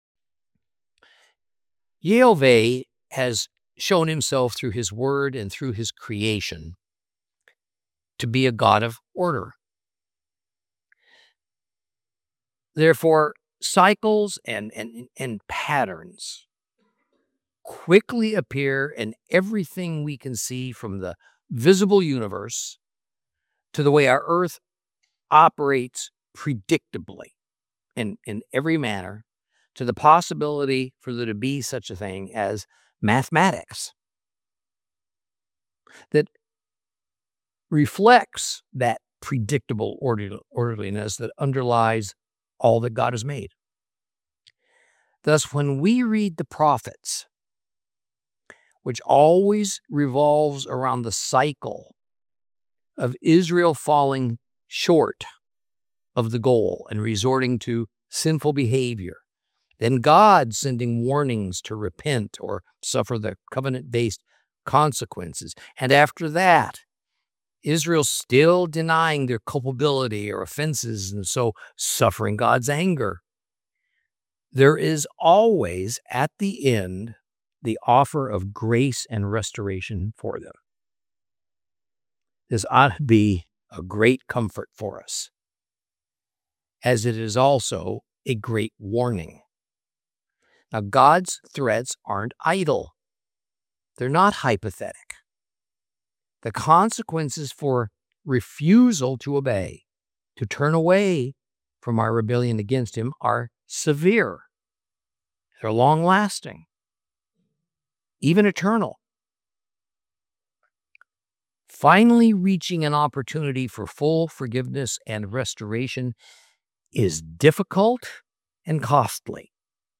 Teaching from the book of Micah, Lesson 14 Chapter 7 continued.